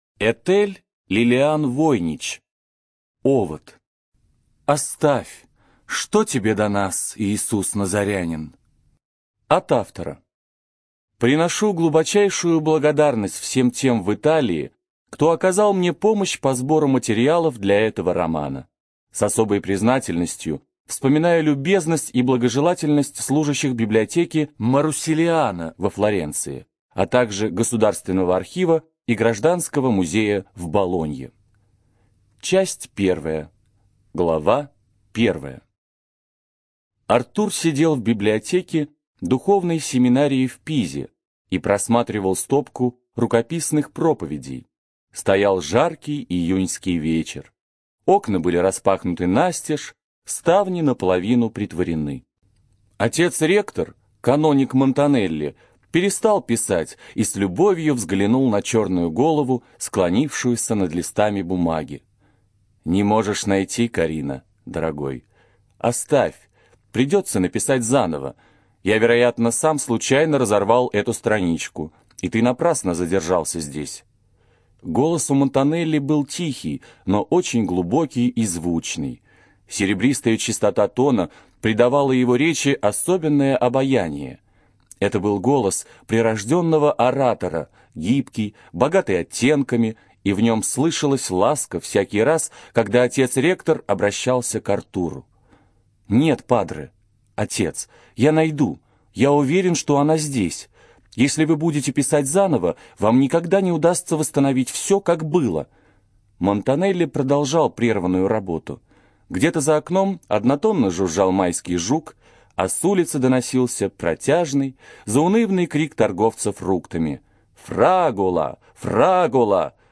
Студия звукозаписиБиблиофоника